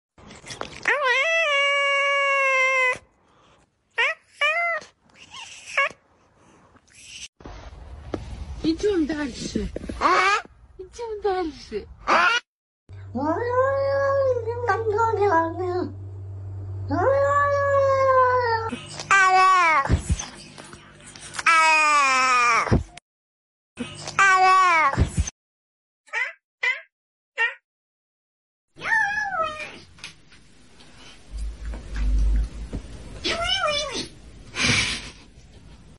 Ranking The Funniest Cat Sounds